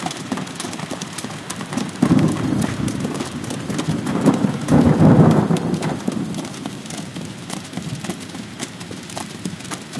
Звук какой-то птицы